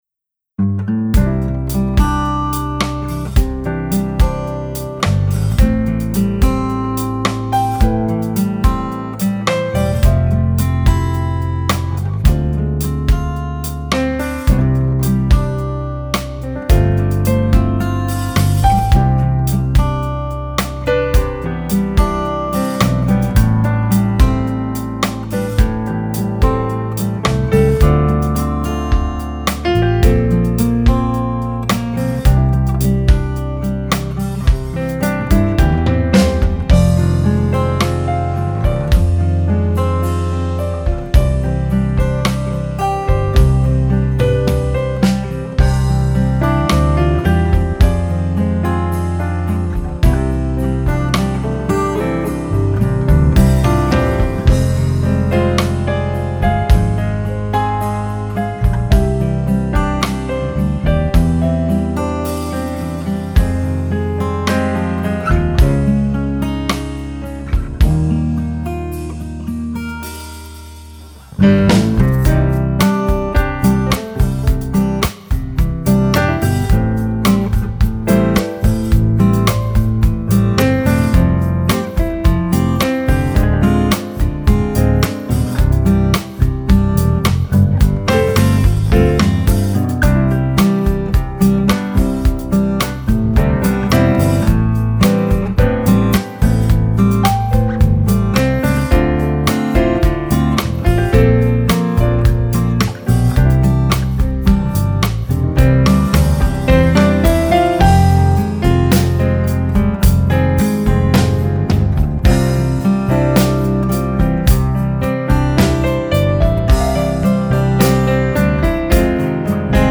• ein neues Lied